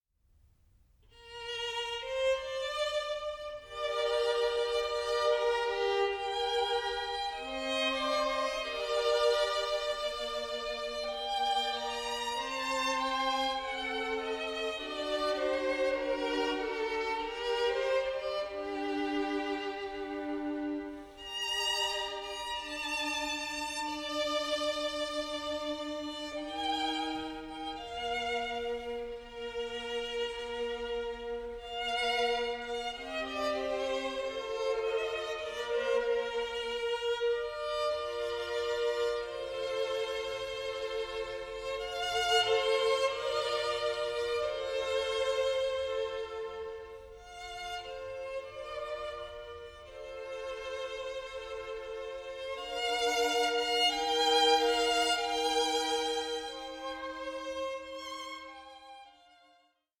Violin Concerto in G, D.78